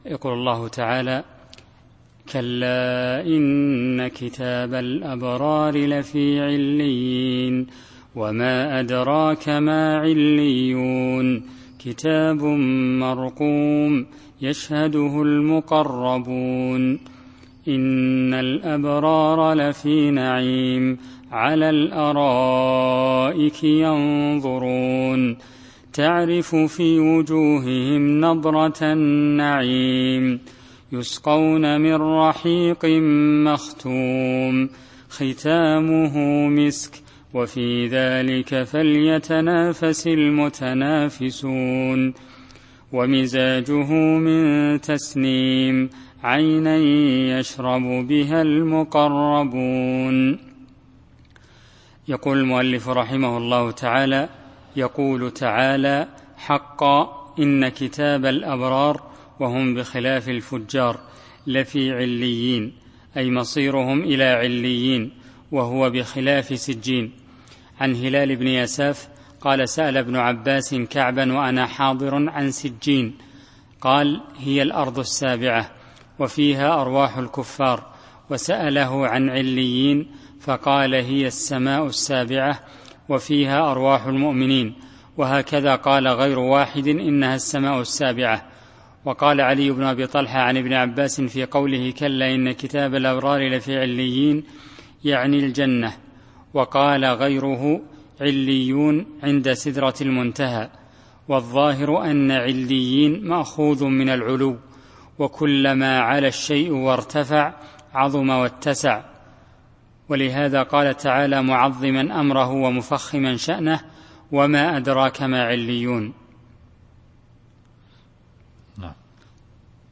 التفسير الصوتي [المطففين / 21]